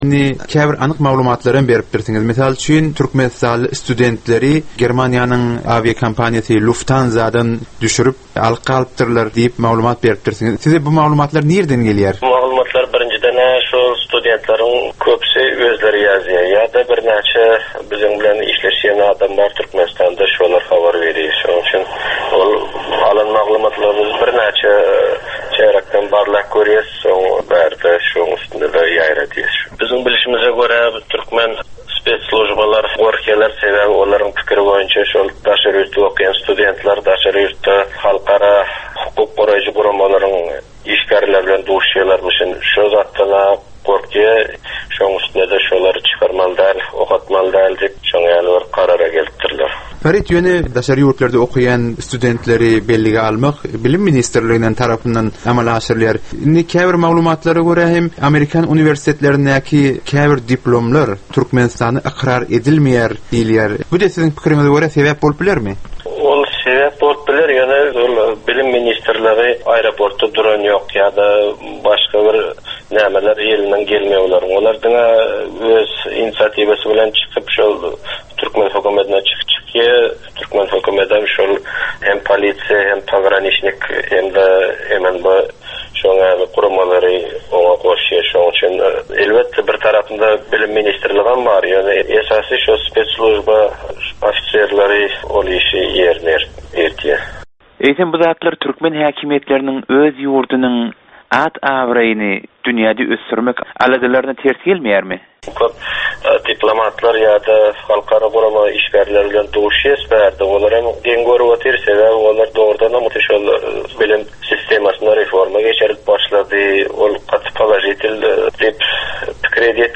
Geplesigin dowmynda aýdym-sazlar hem esitdirilýär.